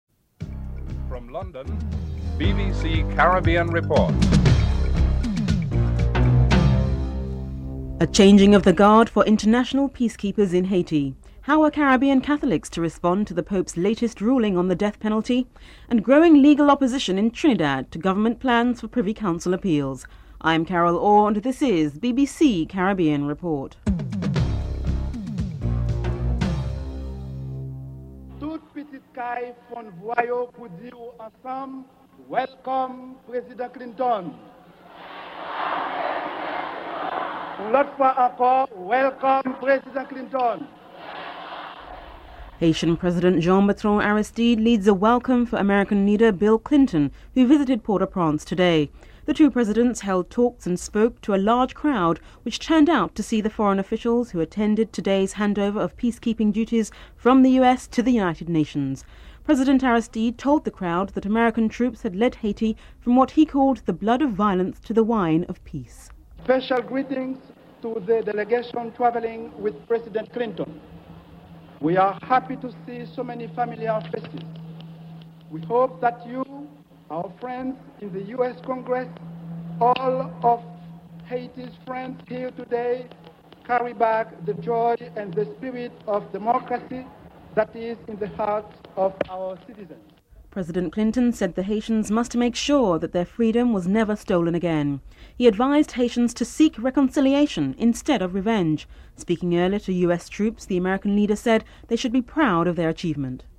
In the Vatican's latest encyclical, Pope John Paul II has denounced the death penalty as morally wrong but has refrained from a blanket condemnation of executions. Archbishop of Kingston, Edgerton Clarke responds to whether he agrees with the Pope's message and how Caribbean Catholics will respond to it.
Former President of the Republic and constitution framer, Sir Ellis Clarke comments on the constitutional bill.